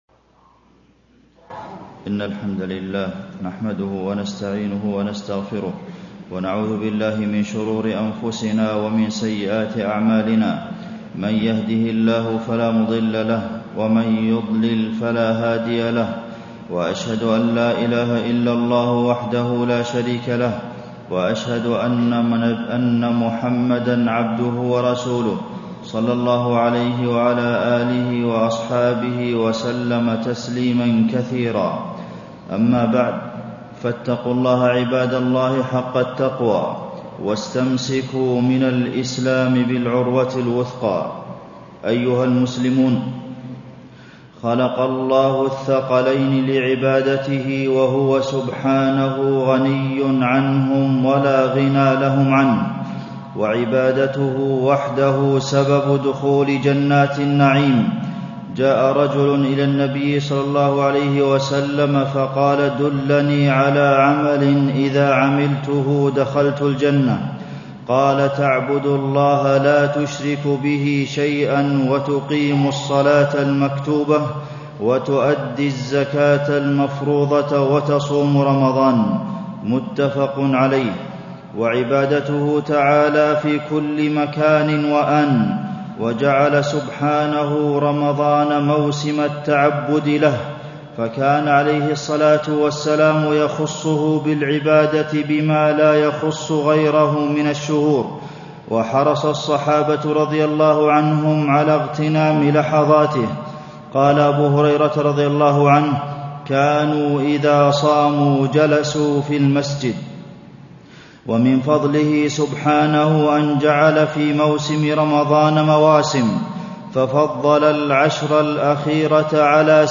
تاريخ النشر ٢٠ رمضان ١٤٣٥ هـ المكان: المسجد النبوي الشيخ: فضيلة الشيخ د. عبدالمحسن بن محمد القاسم فضيلة الشيخ د. عبدالمحسن بن محمد القاسم العشر الأواخر وليلة القدر